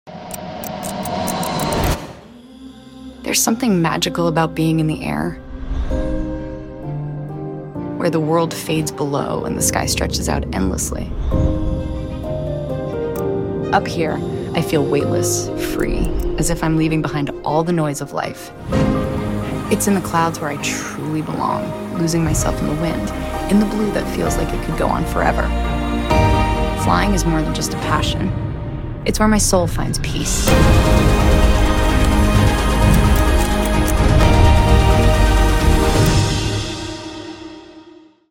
Passion for Aviation ❤easyjet A319